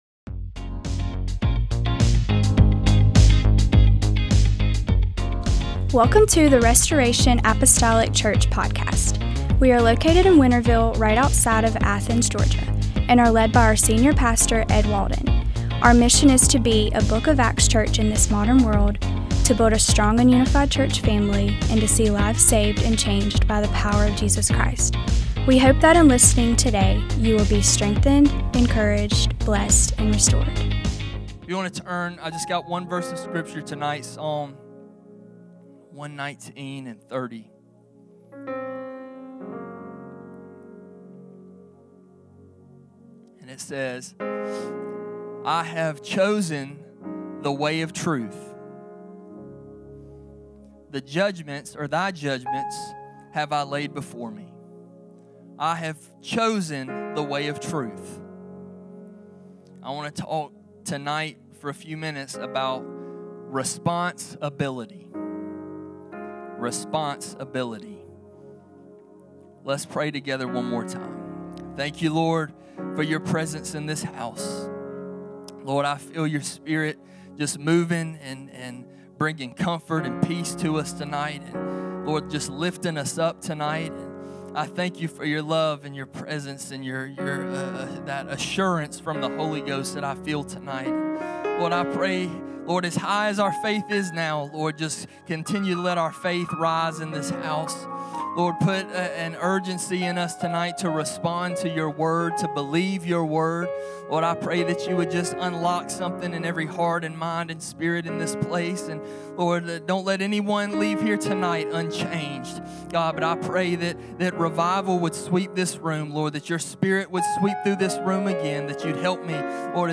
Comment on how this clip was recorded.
MDWK Service - 02/04/2026 - Asst.